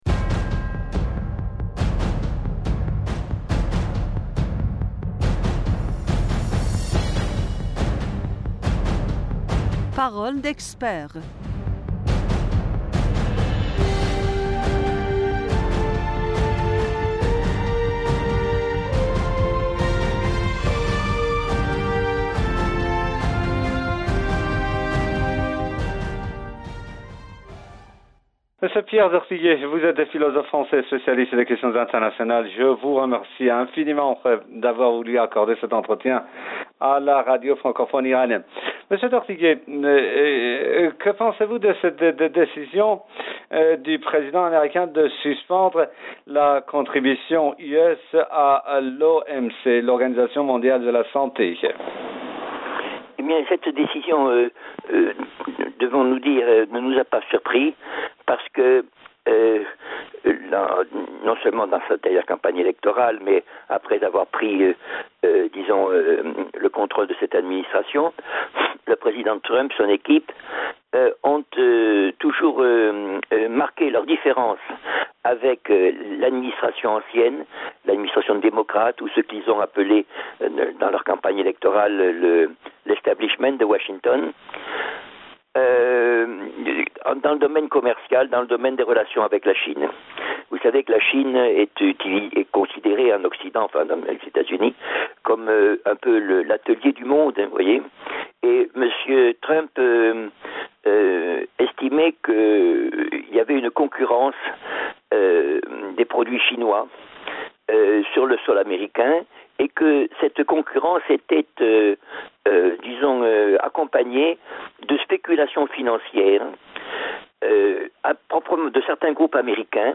table ronde